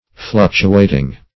fluctuating \fluc"tu*at`ing\ adj.